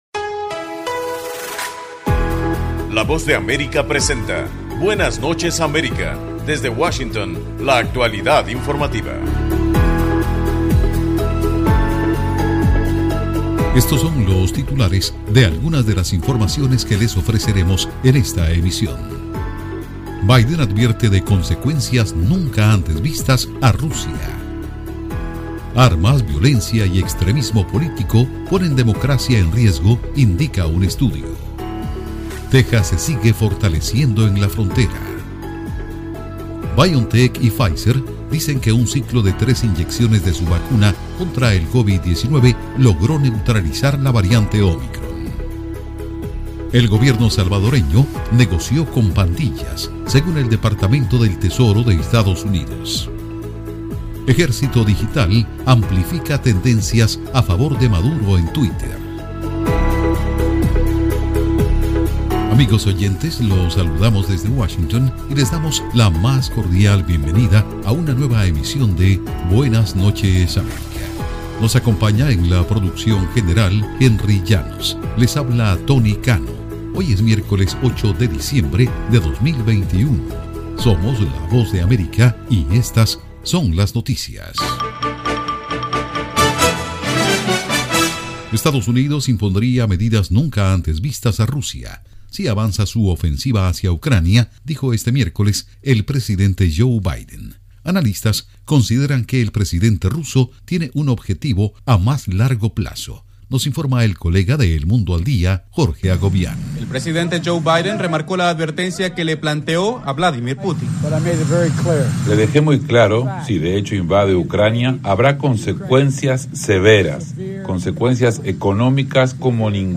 Programa informativo de la Voz de América, Buenas Noches America.